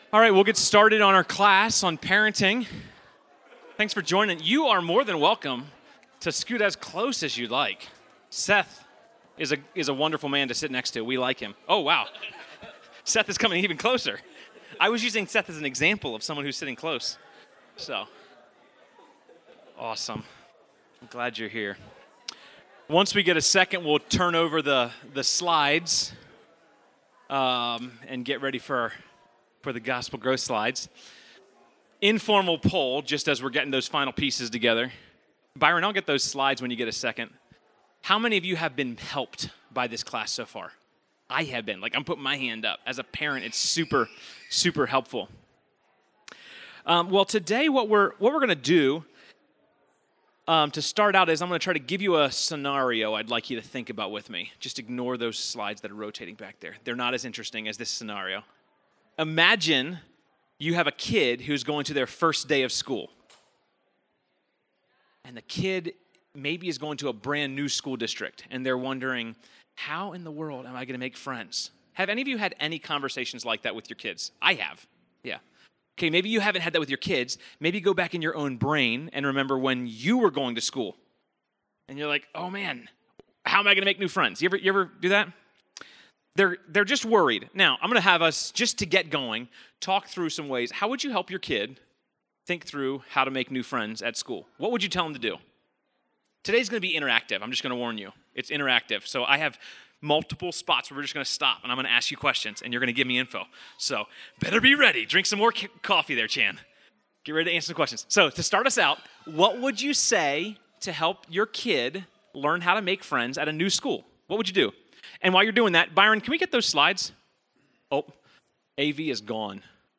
Gospel Grace Church Sermon Audio